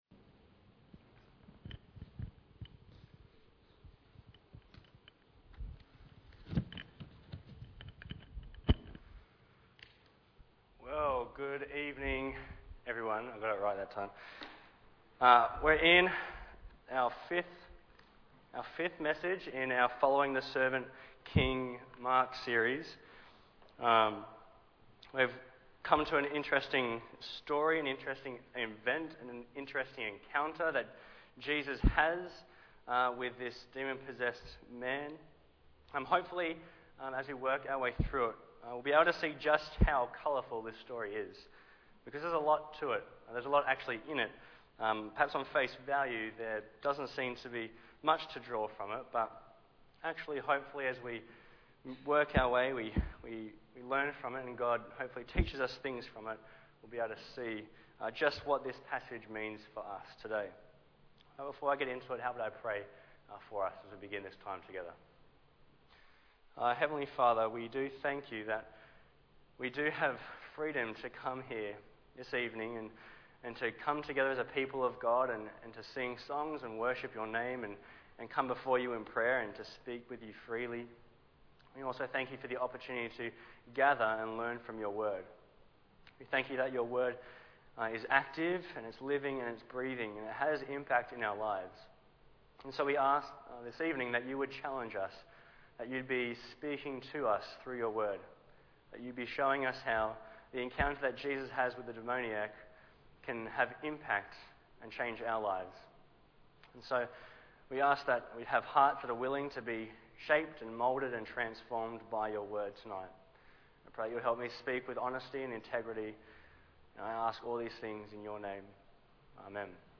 Bible Text: Mark 5:1-20 | Preacher